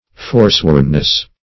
Forswornness \For*sworn"ness\, n. State of being forsworn.